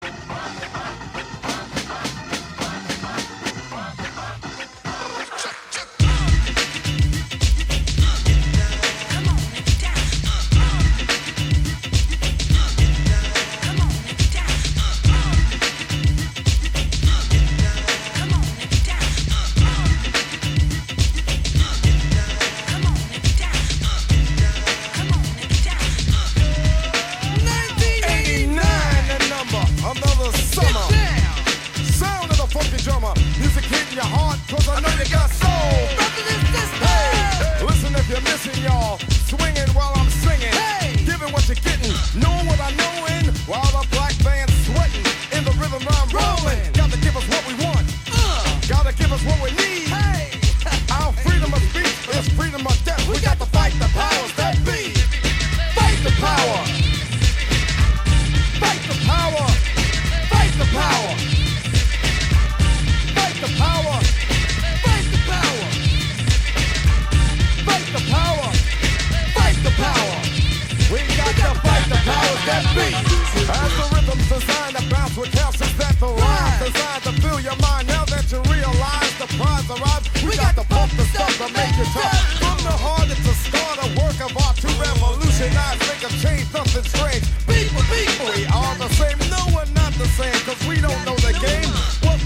a ritmo de hip-hop